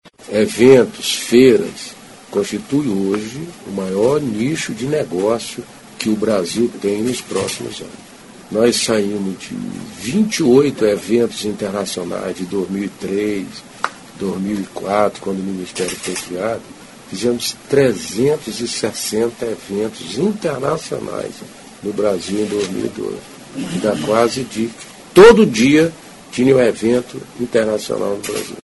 aqui para ouvir declaração do ministro Gastão Vieira sobre a importância do turismo de eventos.